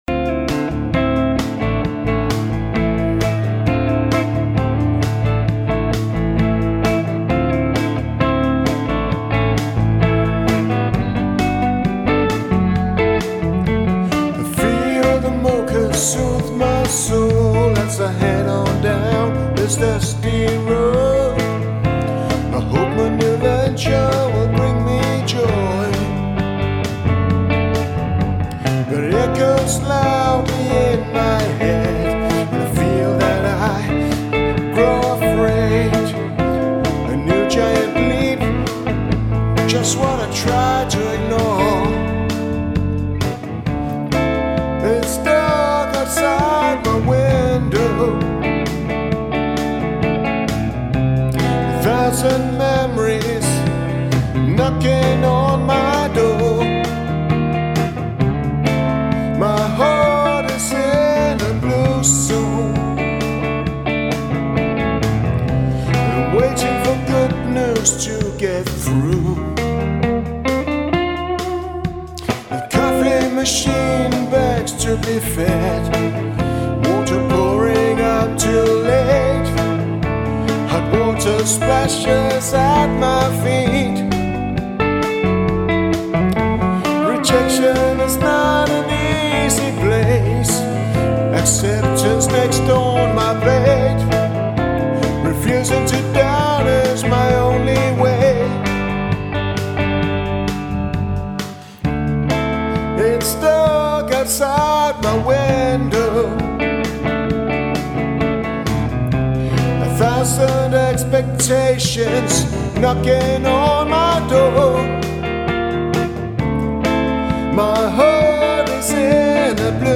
• Americana
• Rock